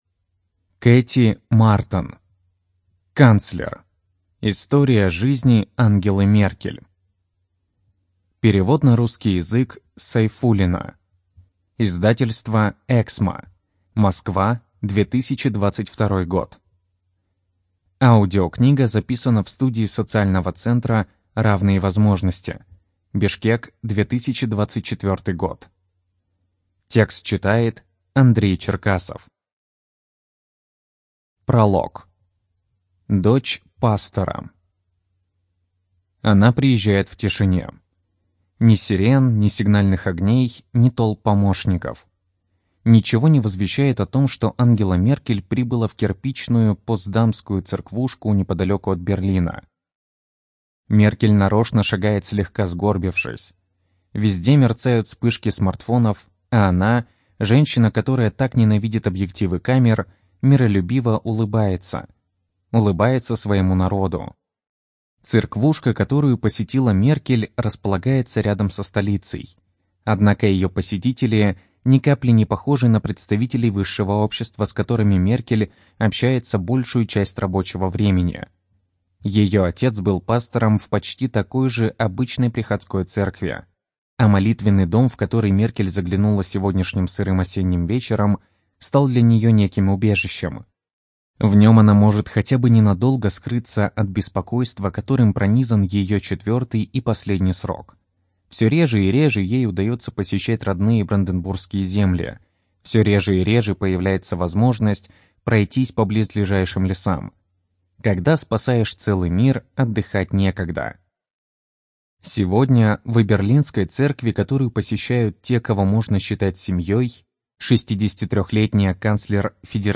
Студия звукозаписиСоциальный центр "Равные возможности" (Бишкек)